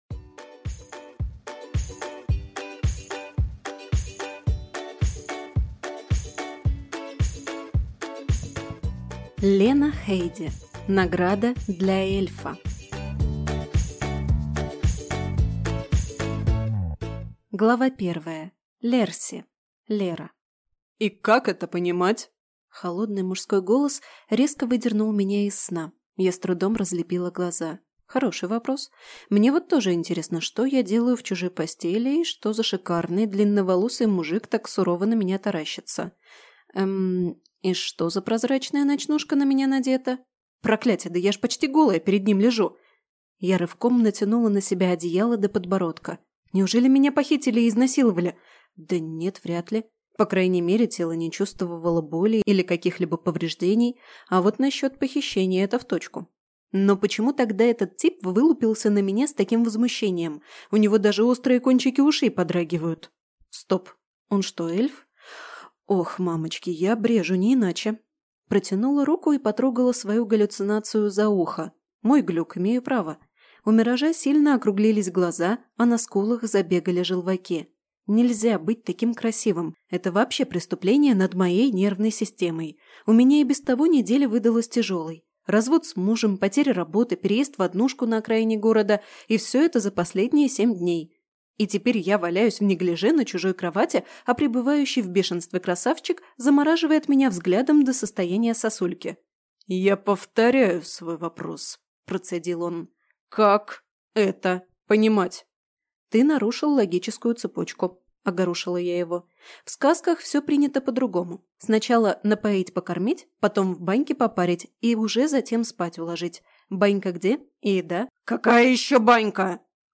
Аудиокнига Награда для эльфа | Библиотека аудиокниг